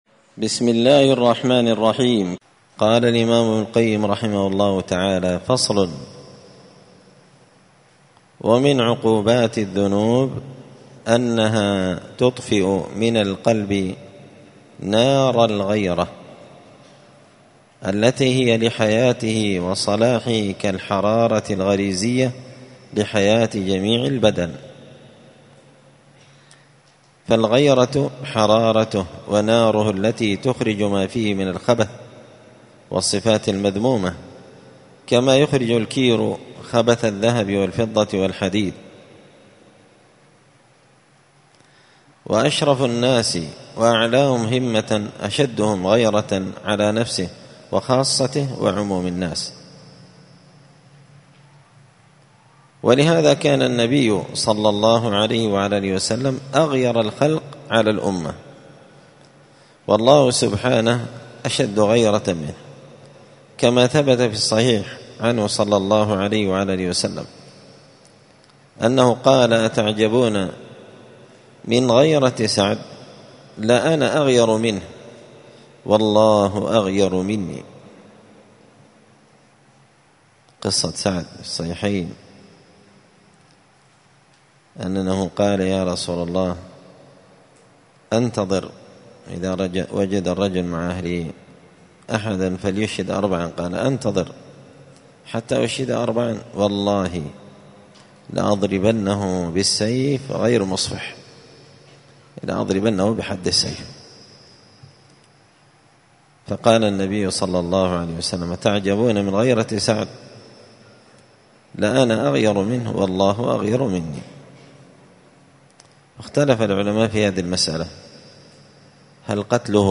دار الحديث السلفية بمسجد الفرقان بقشن المهرة اليمن 📌الدروس الأسبوعية